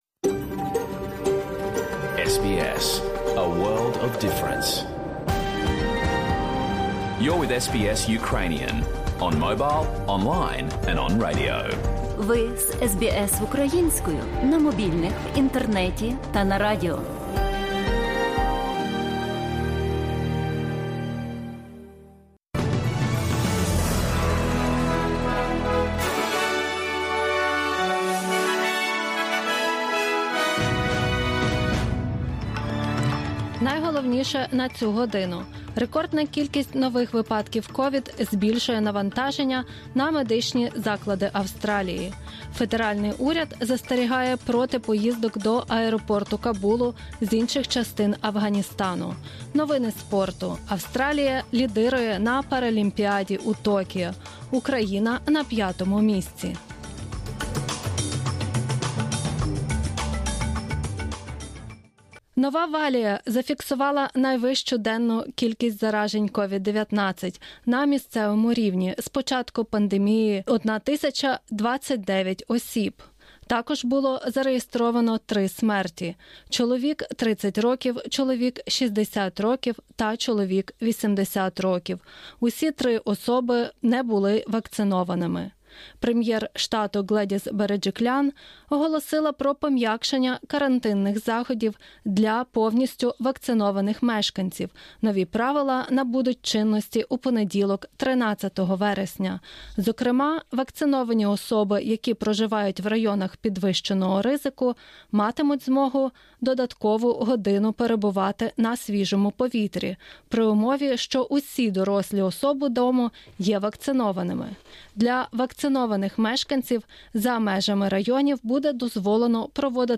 SBS новини українською - 26 серпня 2021